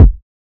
Sickò Kick.wav